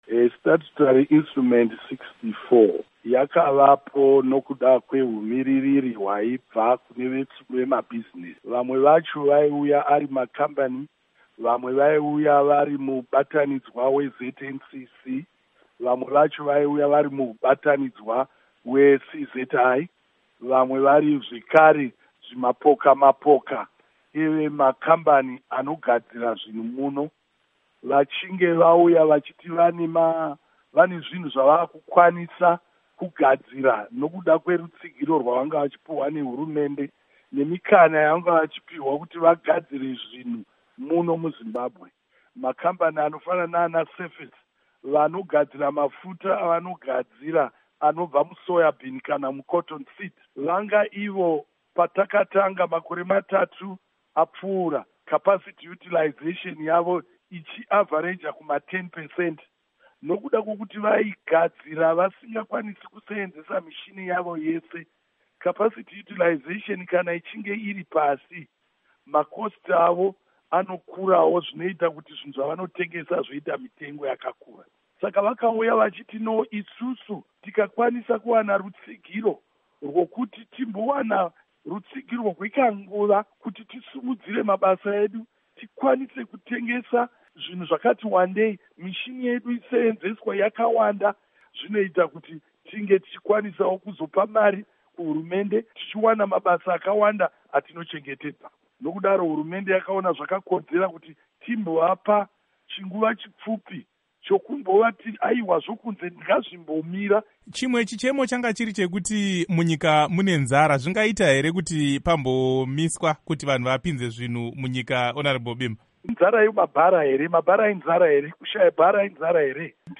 Hurukuro naVaMike Bimha